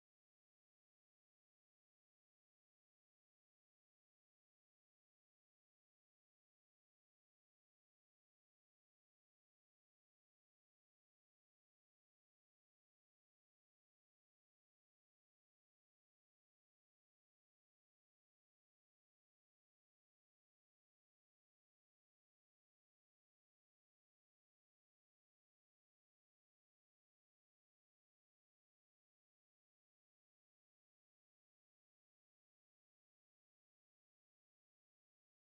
115 BPM Beat Loops Download